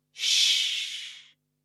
Звуки тс-с
Звук когда человек говорит тише, прикладывая указательный палец к губам и шипя(не болтай) (00:02)